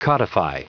Prononciation du mot codify en anglais (fichier audio)
Prononciation du mot : codify